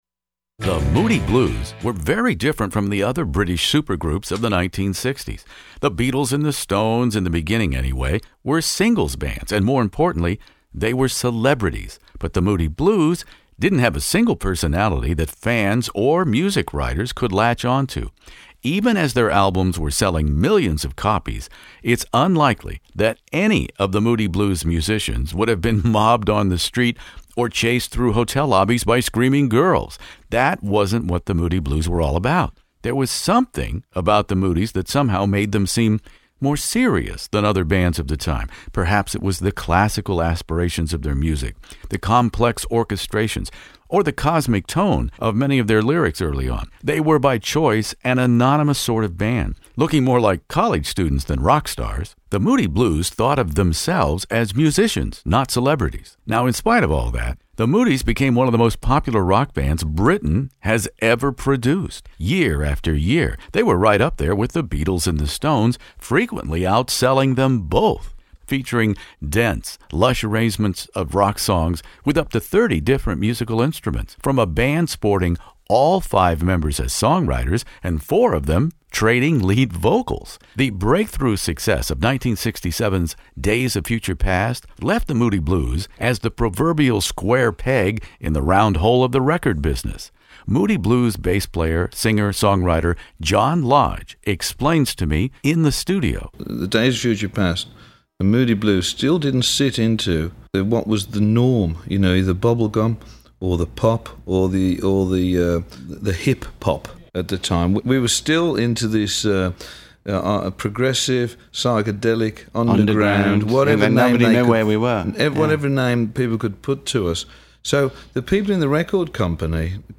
One of the world's largest classic rock interview archives, from ACDC to ZZ Top, by award-winning radio personality Redbeard.
Justin Hayward, Graeme Edge, and John Lodge co-host here In the Studio.